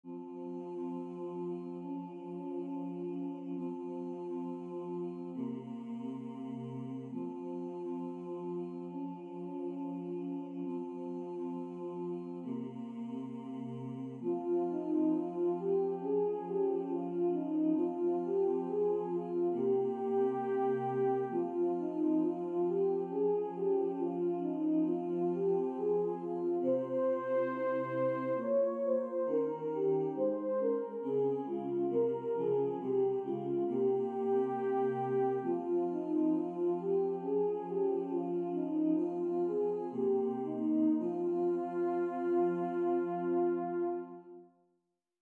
short songs of invocation